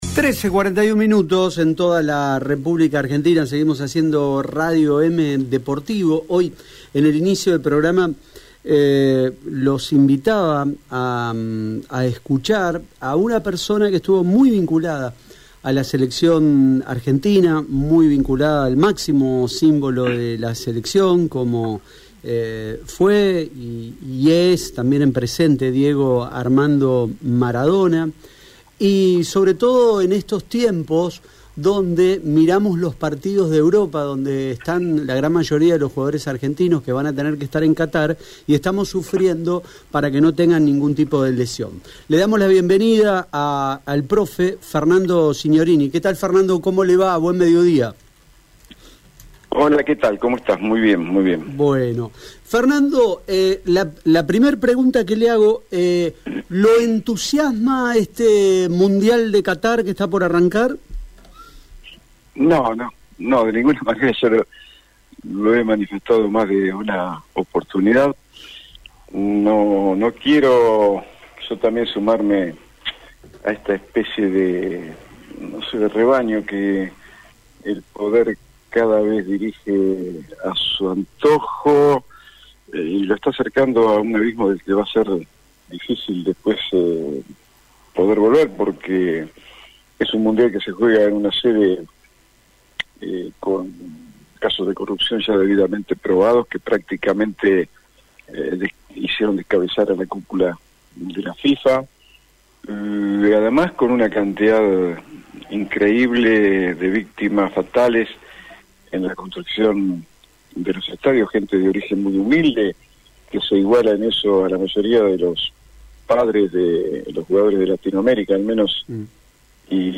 En ese sentido, por su estrecha relación con el astro y Argentina, Radio EME se comunicó con el para hablar del Mundial de Qatar 2022.